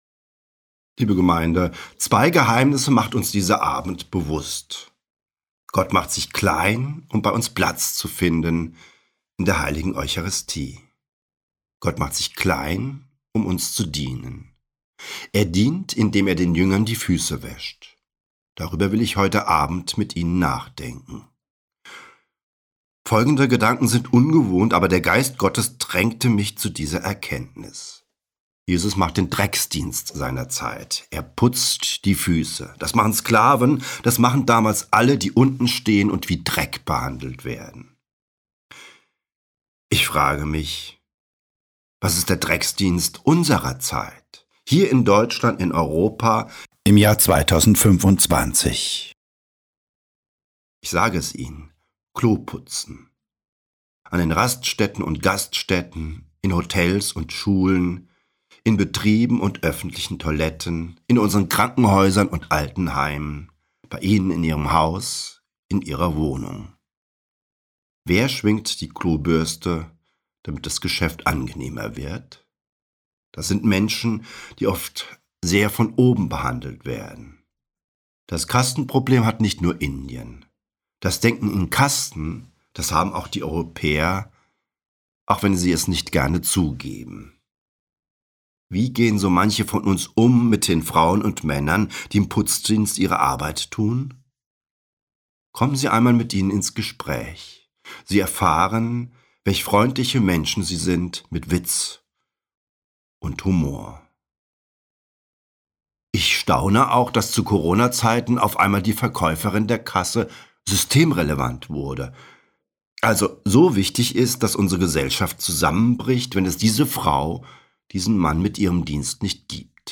Gruendonnerstag-2025-Predigt-1.mp3